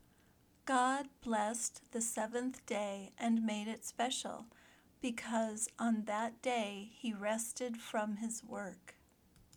If you are learning American English, imitate her pronunciation the best you can.